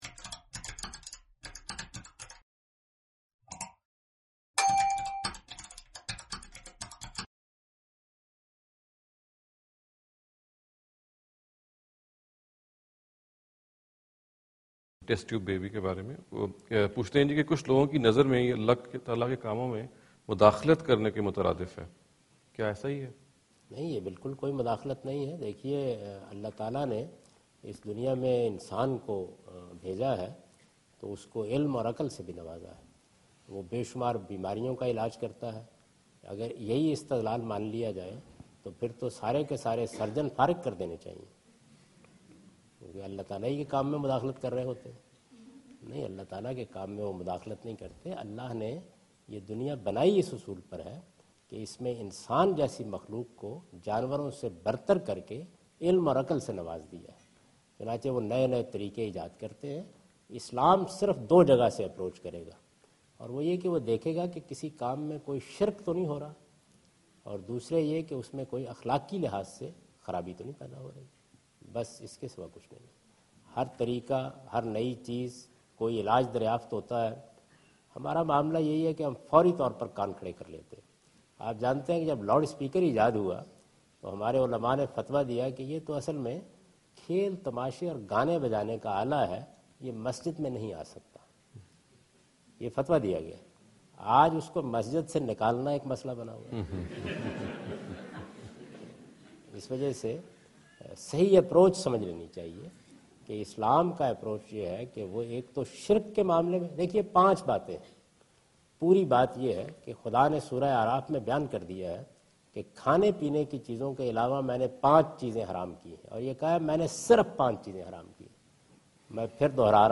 Javed Ahmad Ghamidi answer the question about "Ruling of Islam about Test Tube Babies" during his visit to Georgetown (Washington, D.C. USA) May 2015.
جاوید احمد غامدی اپنے دورہ امریکہ کے دوران جارج ٹاون میں "ٹیسٹ ٹیوب کے ذریعے تخلیق کے بارے میں اسلام کیا کہتا ہے؟" سے متعلق ایک سوال کا جواب دے رہے ہیں۔